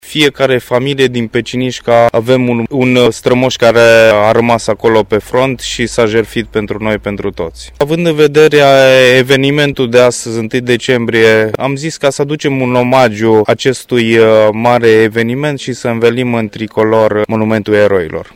Manifestările au început cu ceremonia învelirii în tricolor a Monumentul eroilor din Marele Război ridicat în cimitirul din Pecișca în memoria celor 25 de eroi locali care nu au mai revenit acasă de pe front.